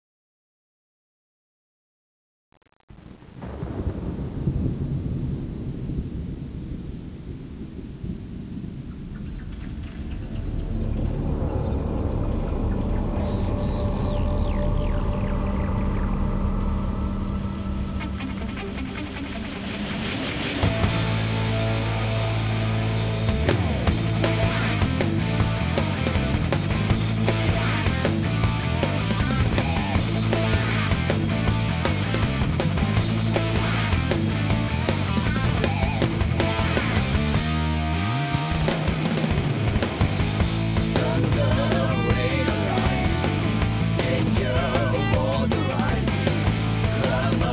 Main theme song (Presentation version)
[11KHz/8Bit/stereo:3.5MB]